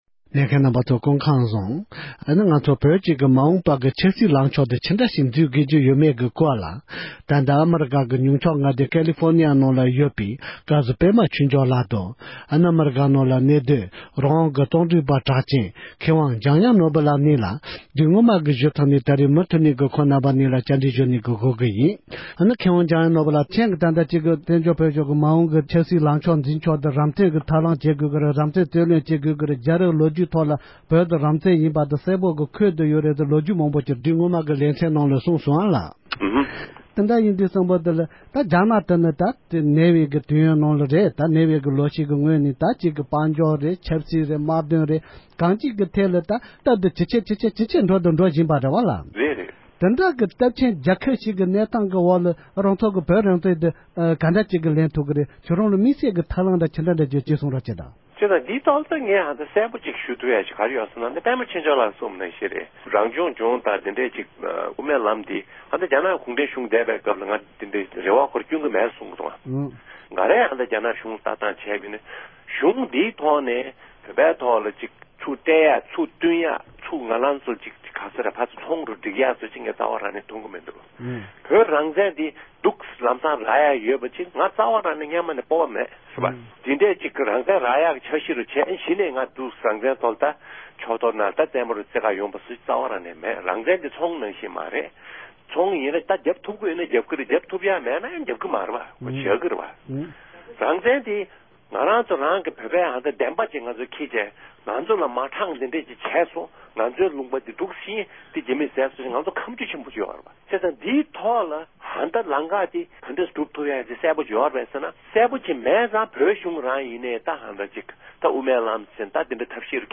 བཀའ་ཟུར་པདྨ་ཆོས་འབྱོར་ལཌ་དང་མཁས་དབང་འཇམ་དབྱངས་ནོར་བུ་ལཌ་རྣམ་གཉིས་ཀྱིས་བོད་ཀྱི་ཆབ་སྲིད་དང་སྲིད་ཇུས་སྐོར་གསུངས་བ།
བཀའ་འདྲི་ཞུས་པ་